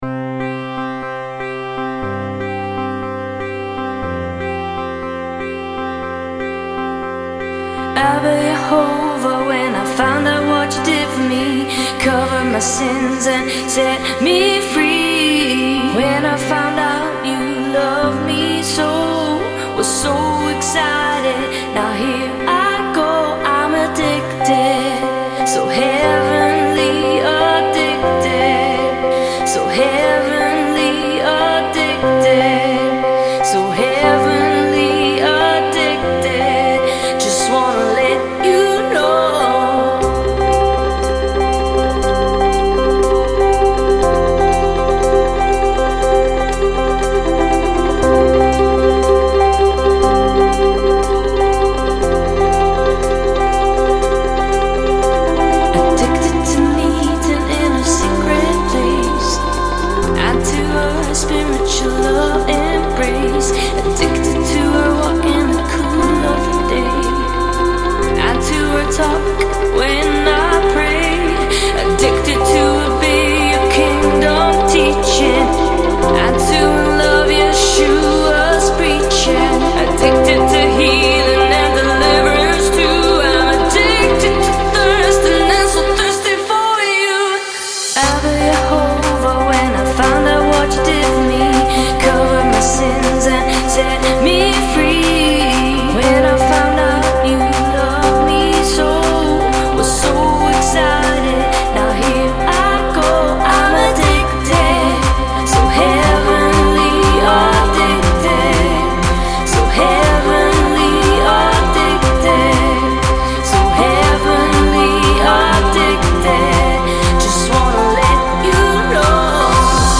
Lead & Background Vocals